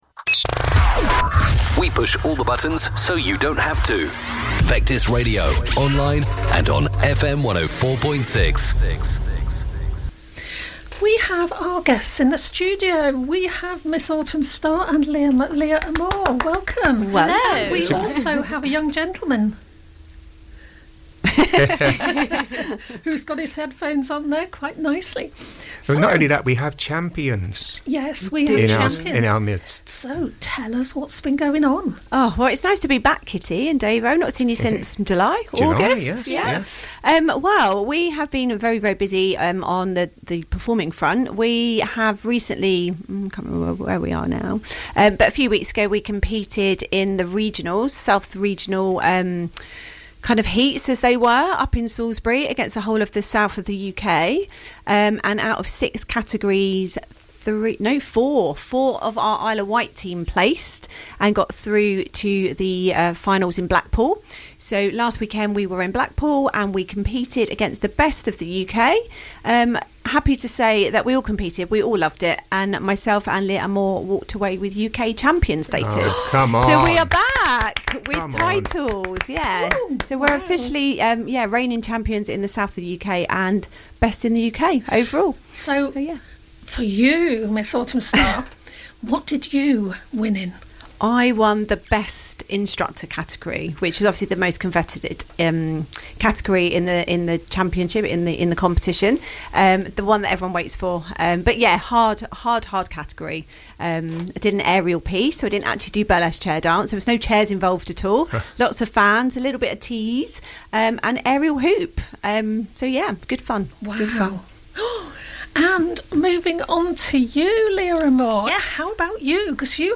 visited the studio to chat about their latest awards, from the Best of Burlesque awards held in Blackpool